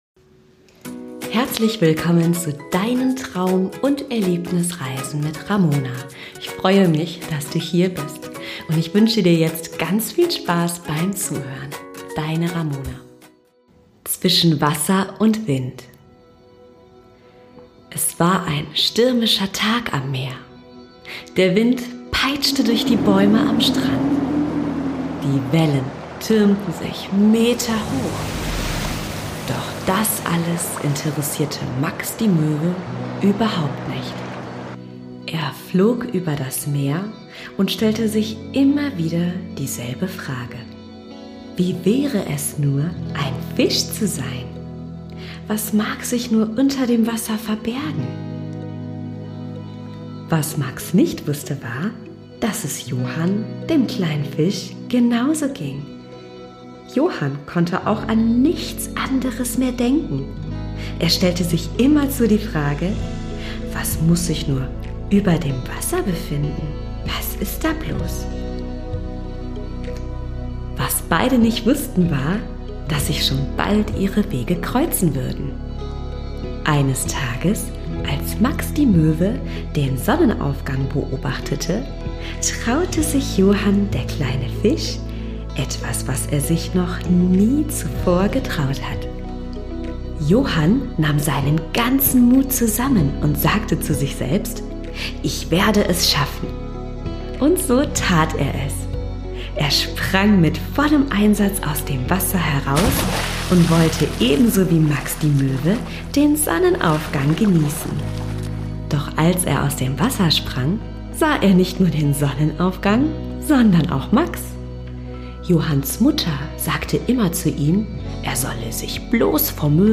eine Traumreise für Kinder